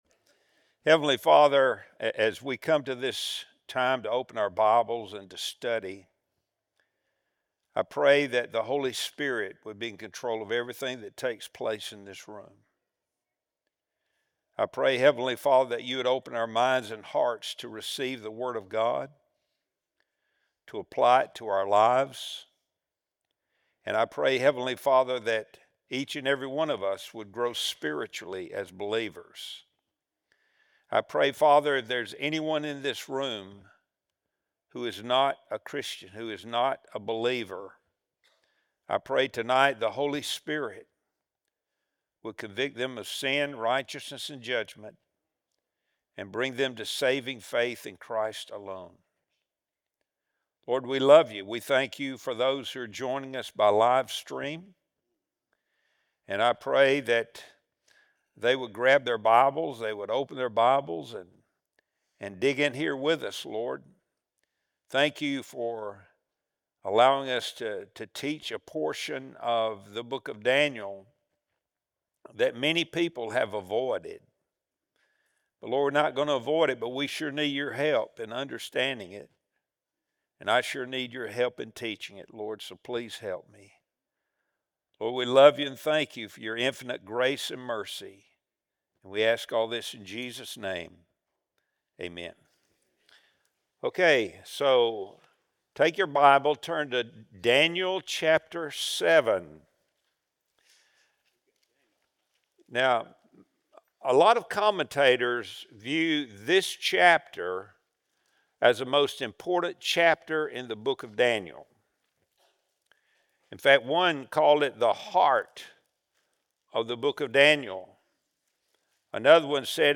Wednesday Bible Study | November 5, 2025